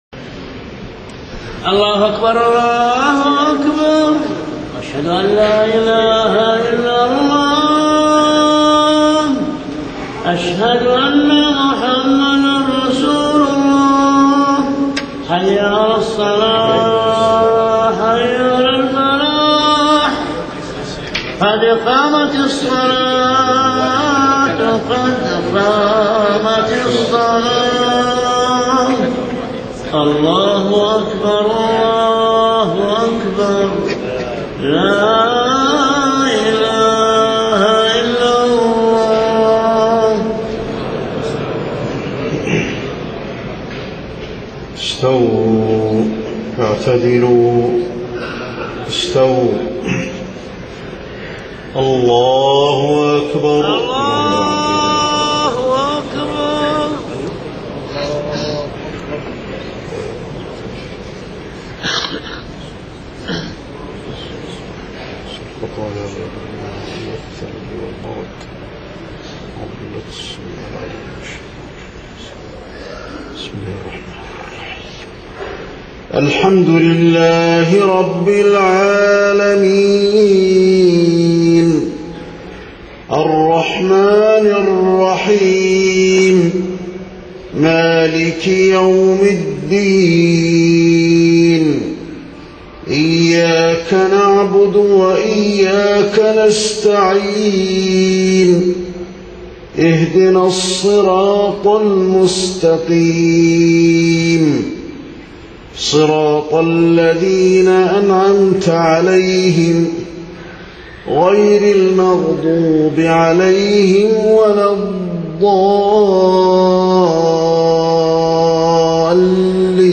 صلاة العشاء 4 صفر 1430هـ خواتيم سورتي الفتح 27-29 والذاريات 55-60 > 1430 🕌 > الفروض - تلاوات الحرمين